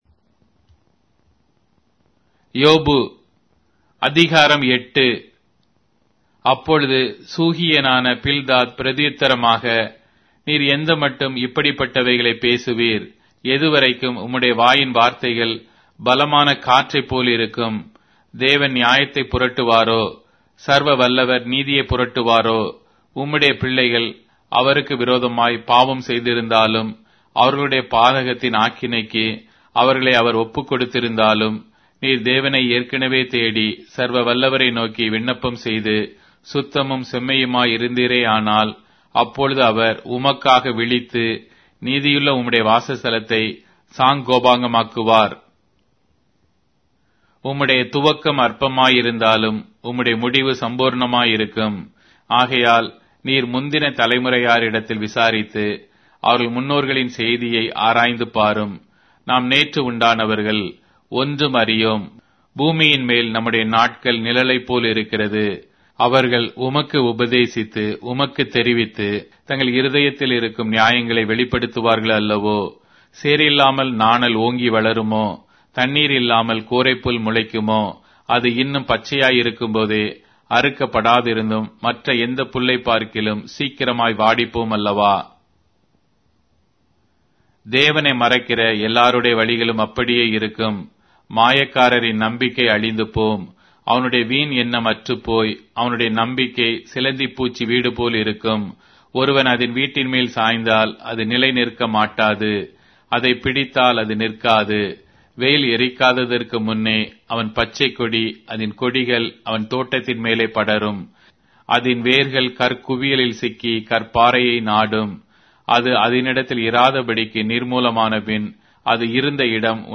Tamil Audio Bible - Job 29 in Ervbn bible version